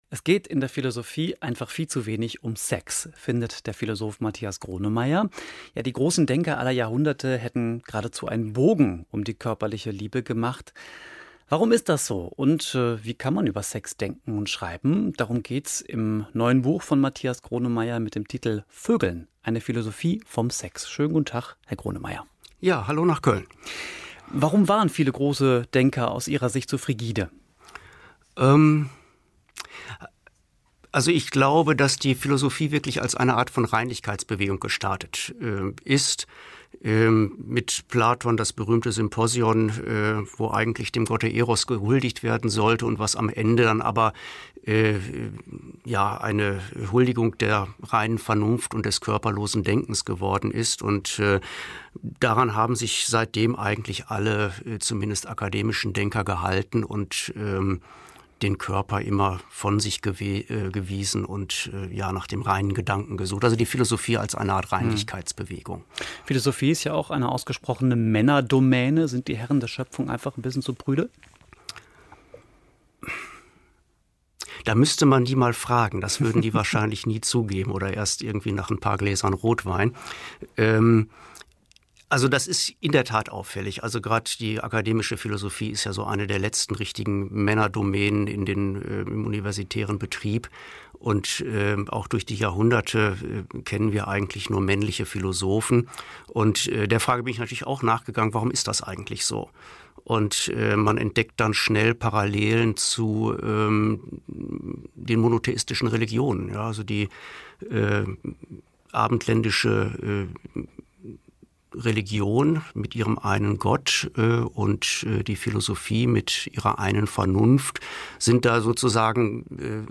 WDR3: Der Autor im Gespräch